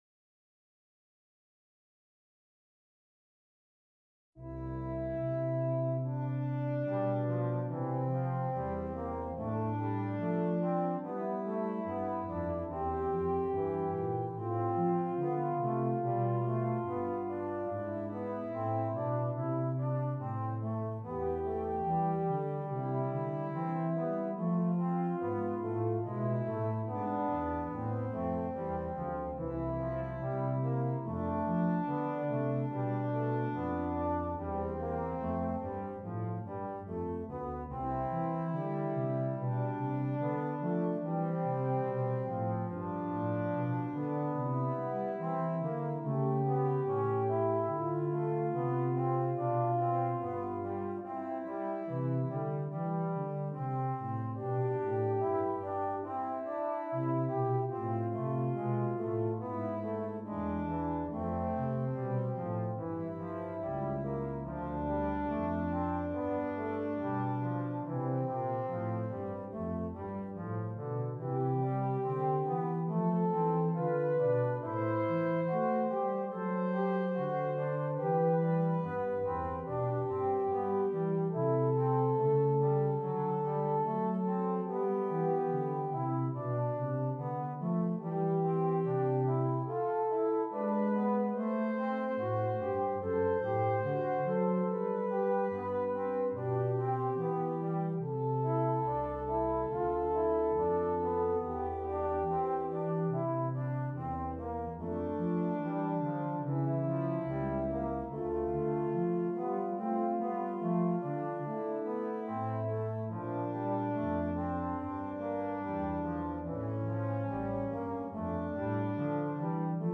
Brass Trio (Horn, Trombone, Tuba)